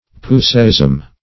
Puseyism \Pu"sey*ism\, n. (Ch. of Eng.)